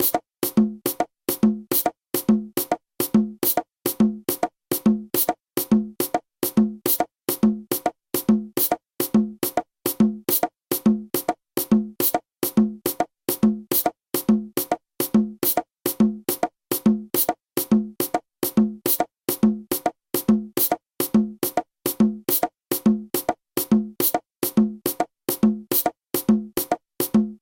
• opiapá, obiapá ou obí-apá, le plus grave. Il produit 2 sons, le golpe abierto et le golpe tapado ;
La musique abakuás possède une signature rythmique en 6/8.
Il existe 2 variantes des rythmes abakuás, l'une venant de la Havane et l'autre de Matanzas.
La variante de la Havane se joue à un rythme très rapide alors que celle de Matanzas est plus lente.
abakua_havane_opiapa.mp3